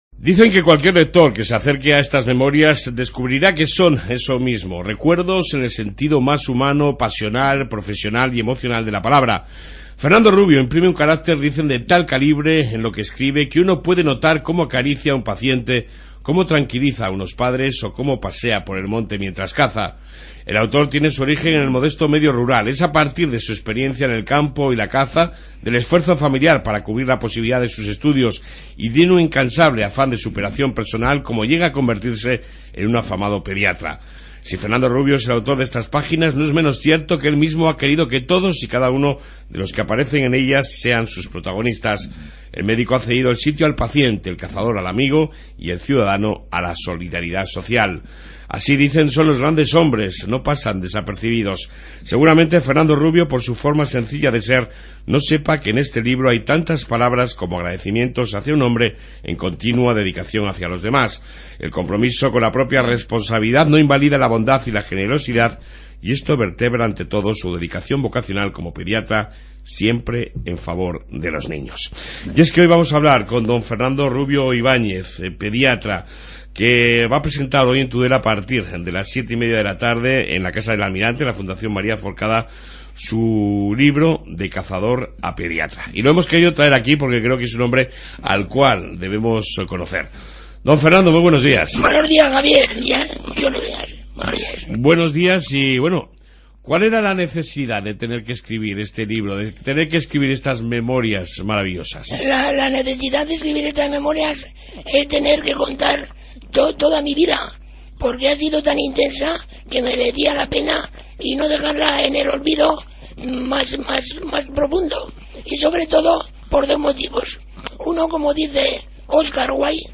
Hoy hemos entrevistado al Pediatra